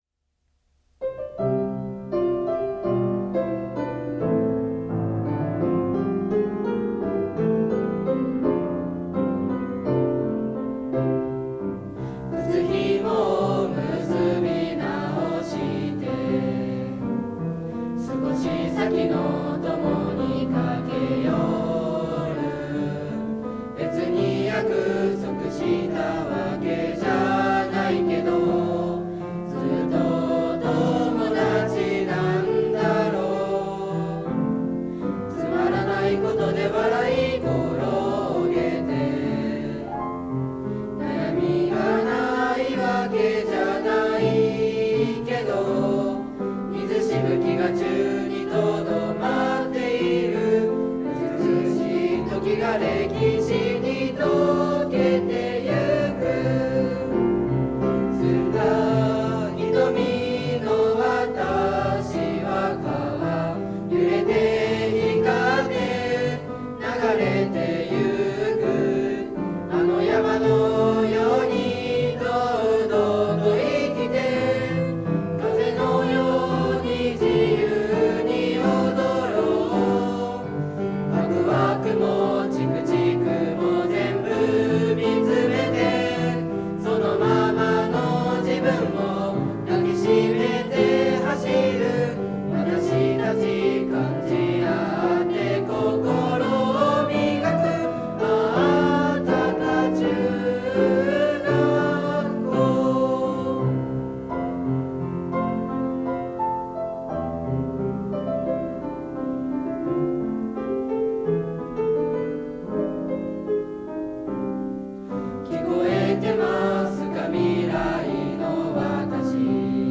多可町らしいゆるやかな流れるメロディーと多可町の風景が思い浮かぶフレーズが特徴的。
多可中学校校歌（歌唱：中町中学校吹奏楽部）(MP3)
多可中学校校歌＿中町中学校吹奏楽部歌唱.mp3